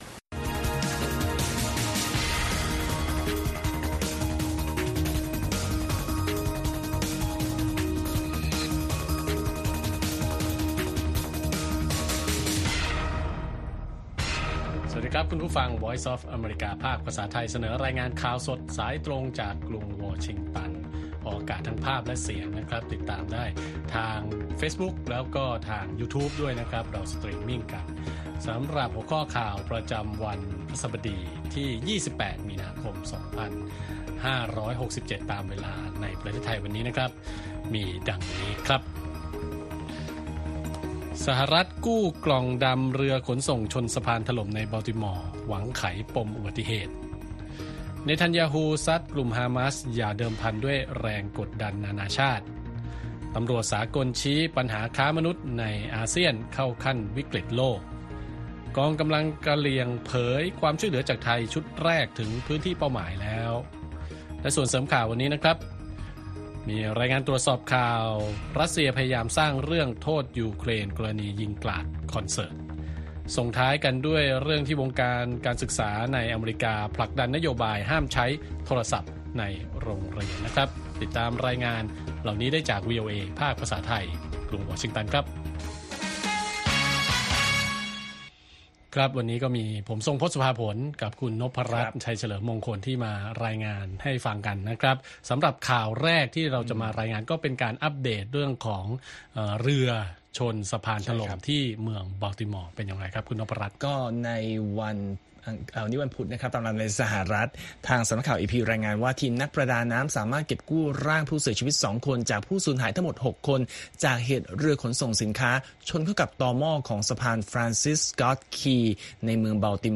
ข่าวสดสายตรงจากวีโอเอไทย 8:30–9:00 น. วันพฤหัสบดีที่ 28 มีนาคม 2567